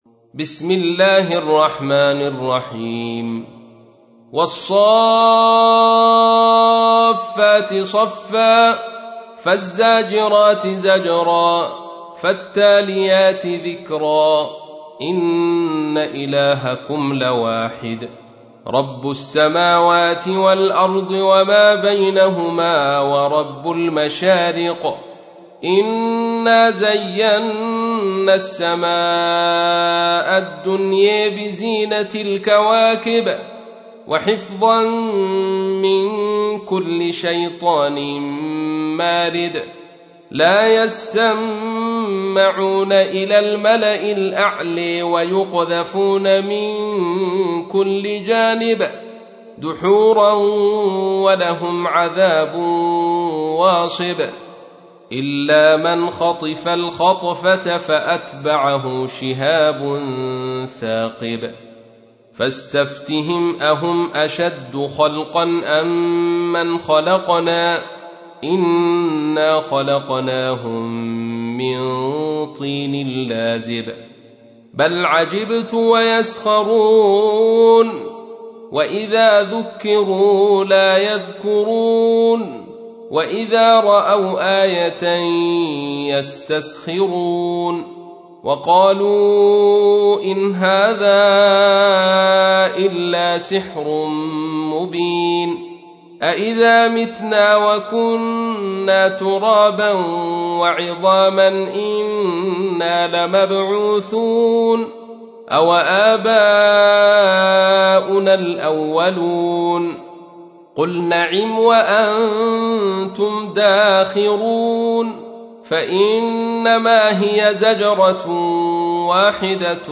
تحميل : 37. سورة الصافات / القارئ عبد الرشيد صوفي / القرآن الكريم / موقع يا حسين